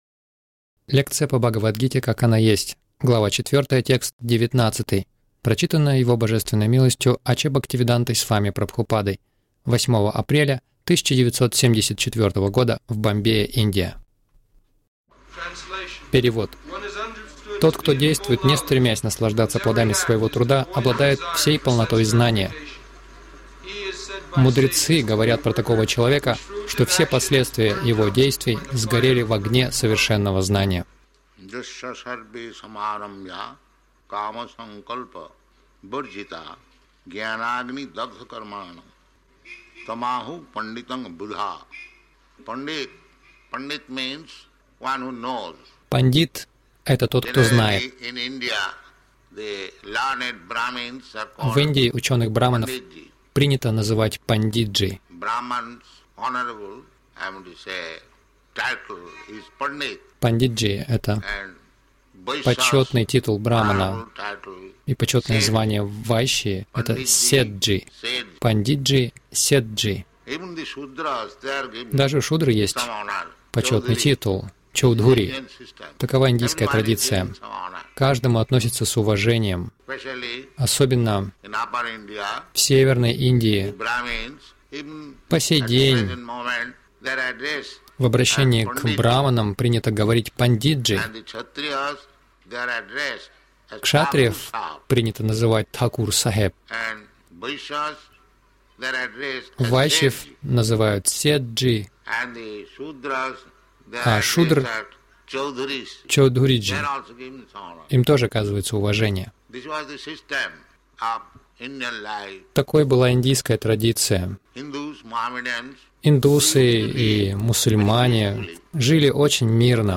Милость Прабхупады Аудиолекции и книги 08.04.1974 Бхагавад Гита | Бомбей БГ 04.19 — Кто пандит.